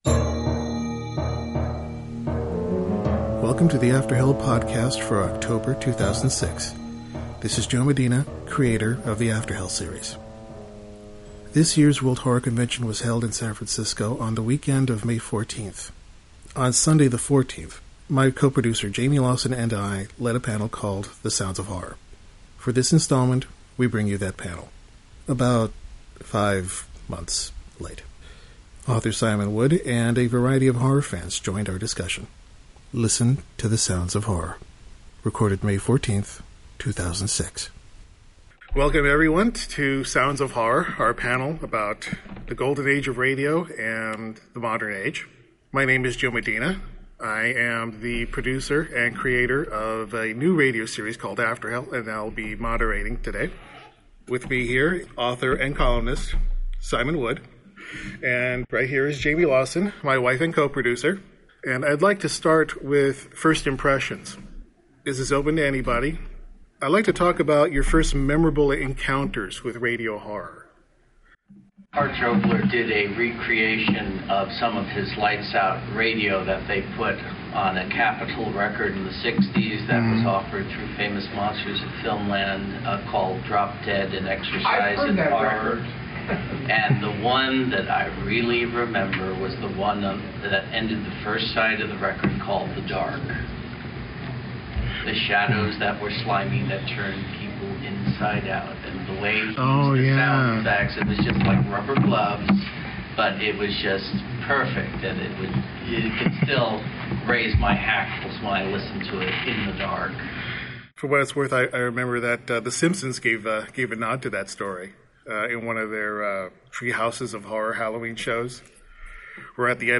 Apparently, no matter how many times I try, I am genetically incapable of articulating the letter “m” in front of a _ike.
Afterhell Sounds of Horror panel Oct2006.mp3